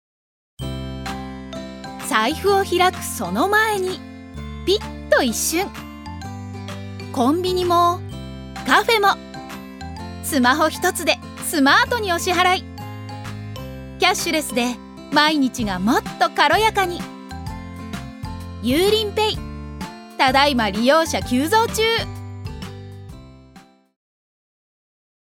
女性タレント
ナレーション５